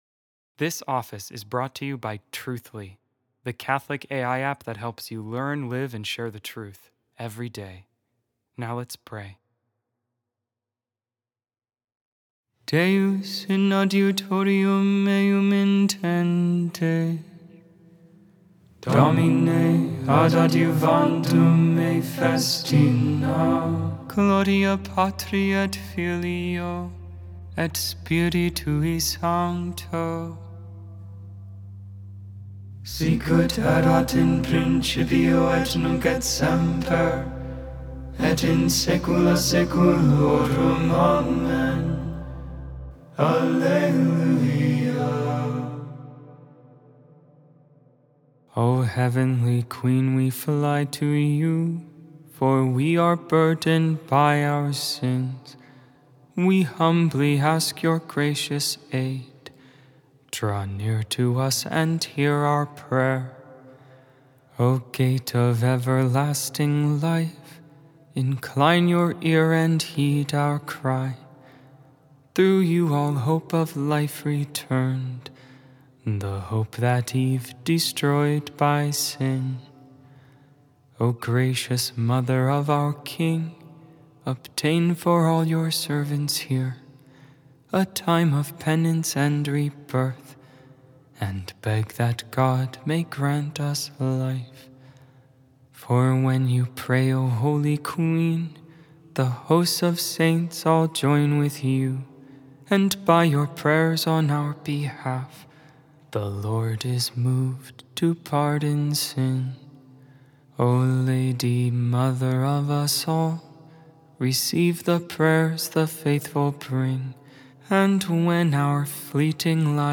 Vespers